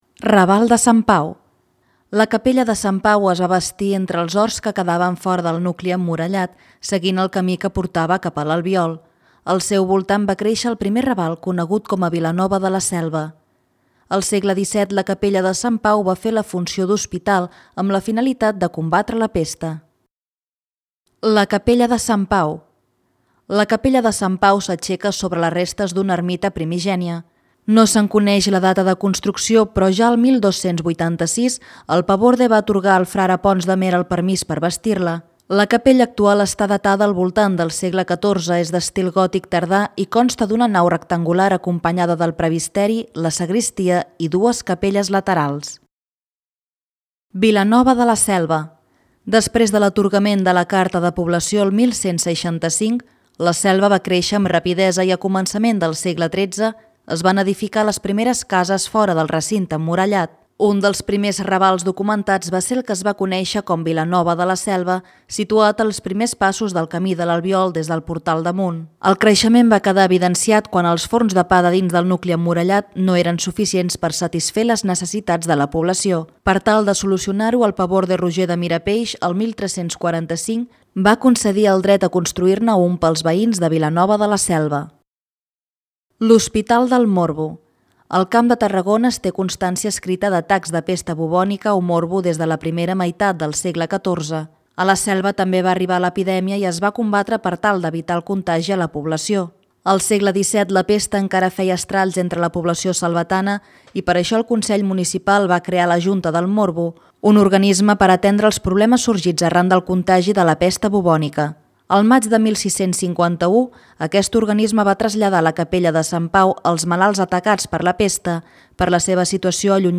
Audio guia